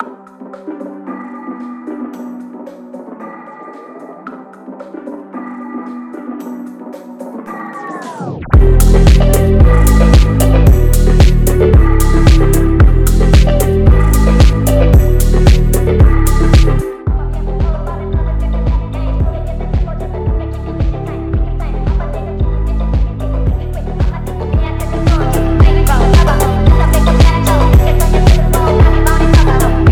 Жанр: Электроника